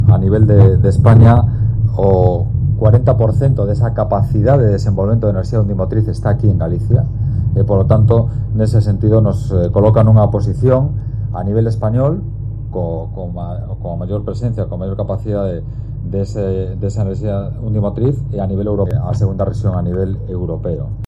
En la presentación de la boya, el conselleiro de Industria, Francisco Conde, ha destacado que Galicia es la segunda región europea, tras Gales del Sur, con mayor capacidad de generar este tipo de energía renovable: